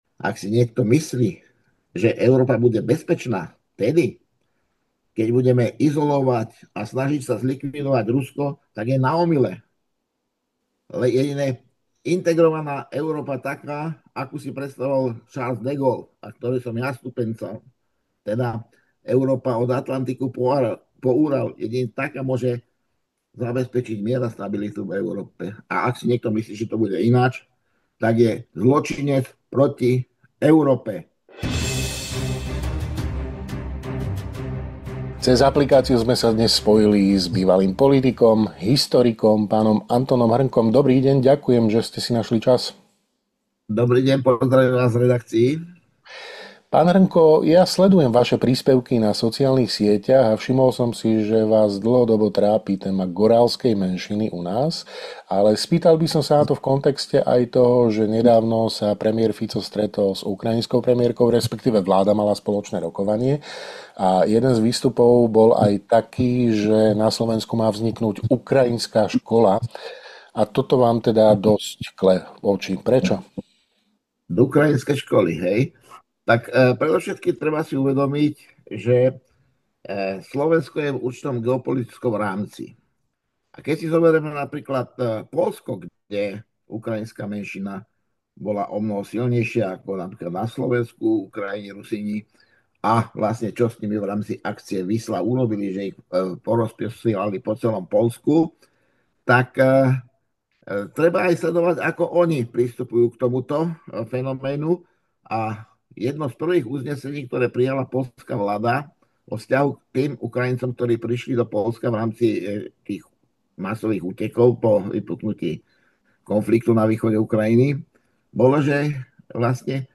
Viac sa dozviete vo videorozhovore s historikom a bývalým politikom, PhDr. Antonom Hrnkom, CSc.